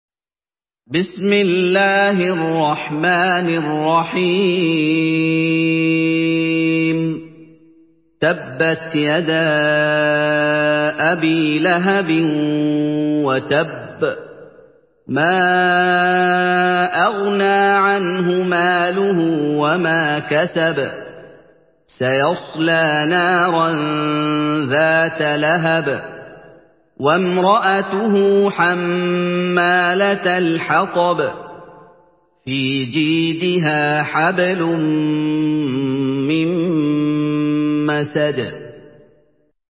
سورة المسد MP3 بصوت محمد أيوب برواية حفص
سورة المسد MP3 بصوت محمد أيوب برواية حفص عن عاصم، استمع وحمّل التلاوة كاملة بصيغة MP3 عبر روابط مباشرة وسريعة على الجوال، مع إمكانية التحميل بجودات متعددة.
مرتل